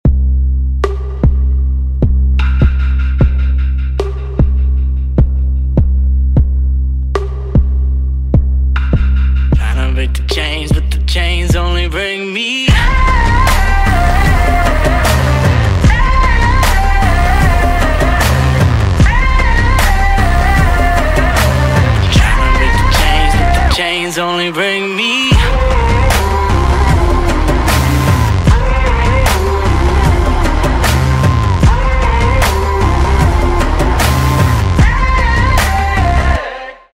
• Качество: 320, Stereo
Alternative Hip-hop